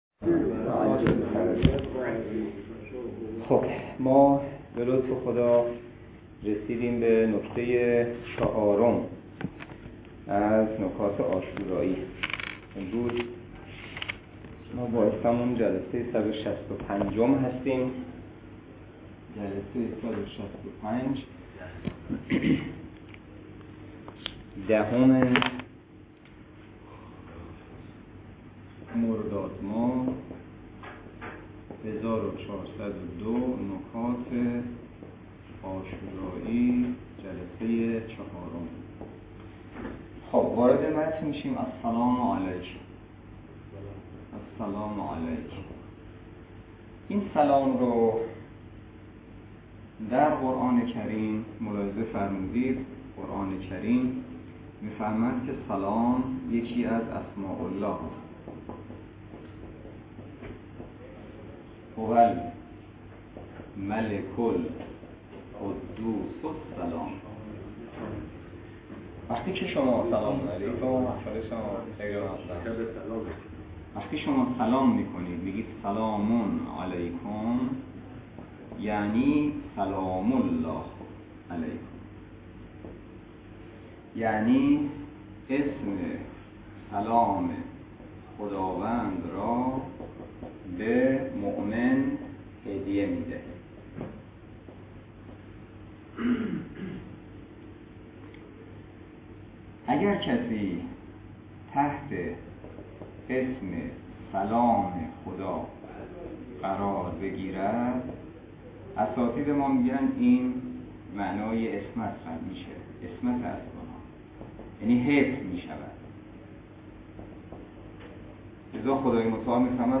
درس فقه الاجاره نماینده مقام معظم رهبری در منطقه و امام جمعه کاشان - جلسه صد و شصت و پنج .